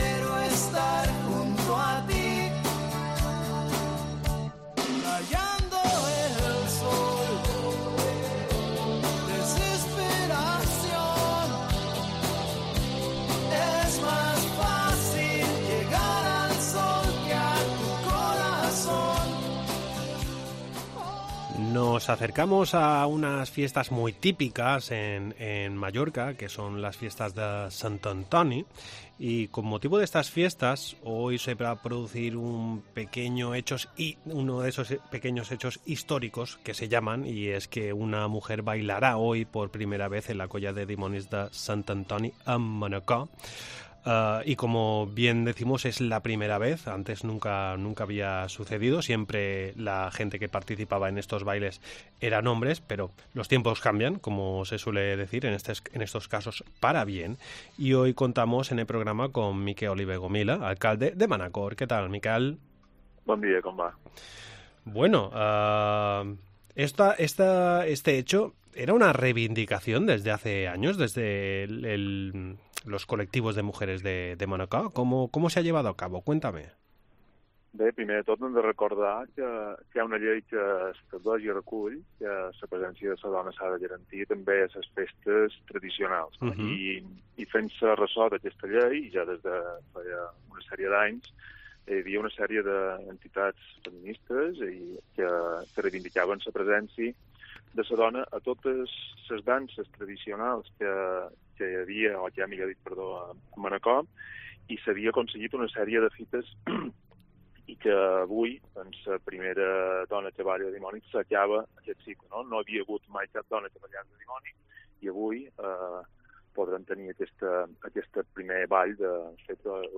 El alcalde de Manacor, Miquel Oliver Gomila, nos habla sobre las fiestas de Sant Antoni, muy típicas de esta localidad.